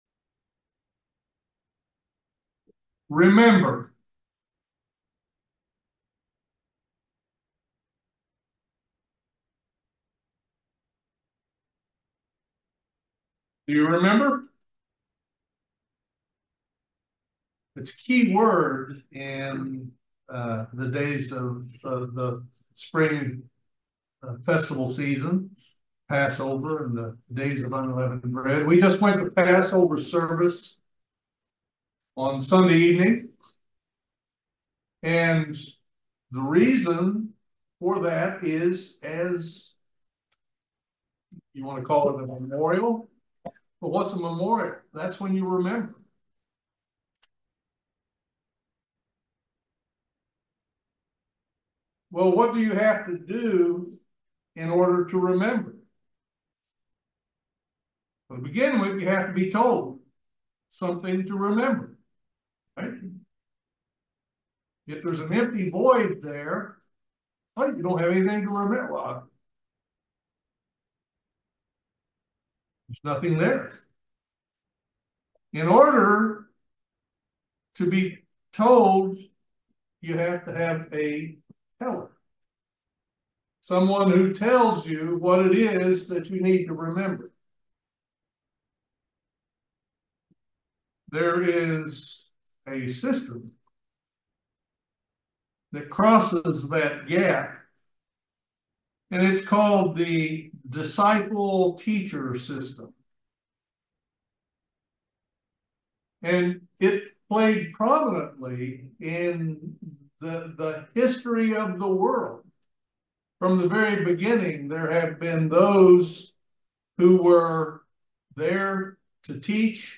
Join us for this eye opening video Sermon on this subject.